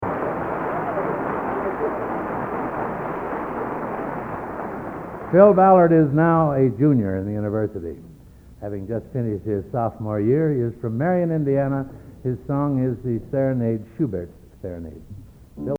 Collection: End of Season, 1971
Location: West Lafayette, Indiana
Genre: | Type: Director intros, emceeing |End of Season